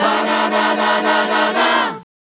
Ba Da, Ba Da Lik 152-B.wav